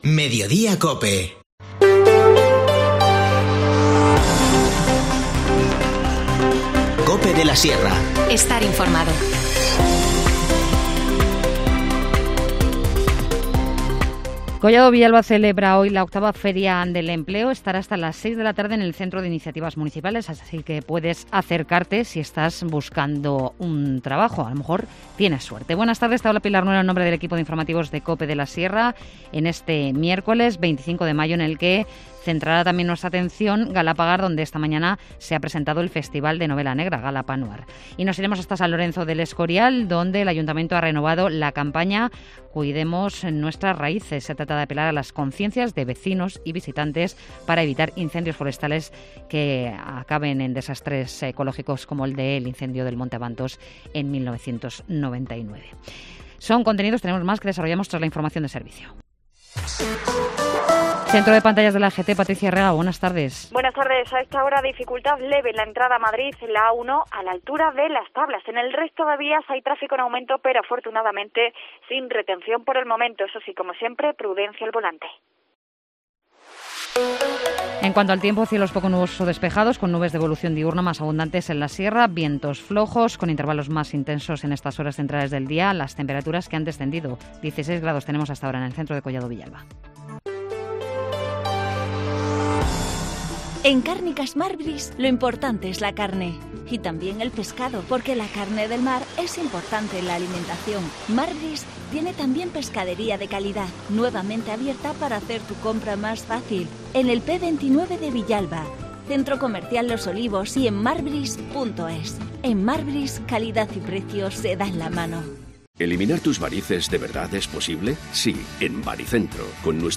Informativo Mediodía 25 mayo